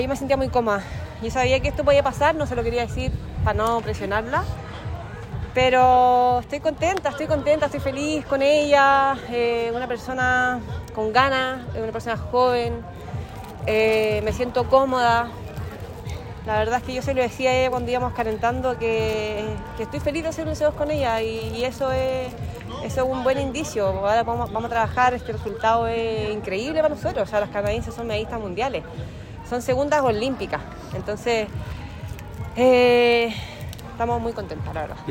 Al término de la prueba, y visiblemente emocionadas, ambas deportistas compartieron sus sensaciones.